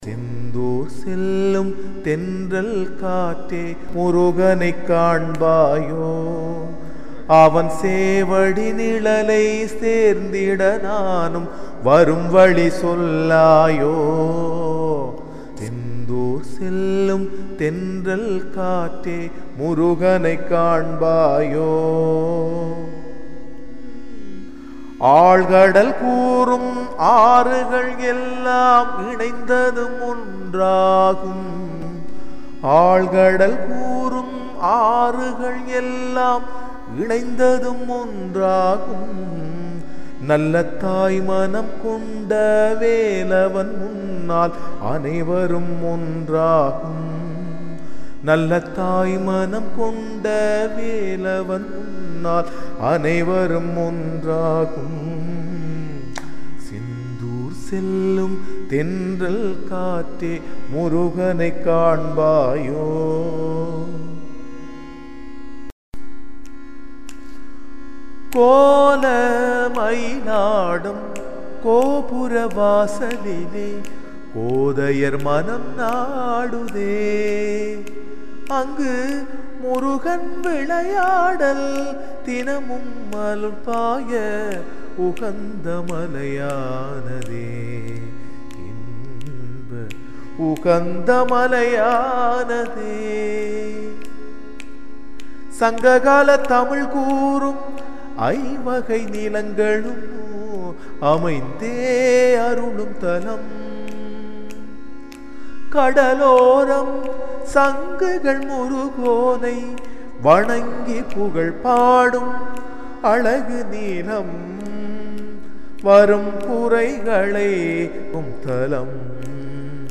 என்னை மிகவும் வசிகரிக்கும் இனிமையான குரலுக்கு சொந்தக்காரன்.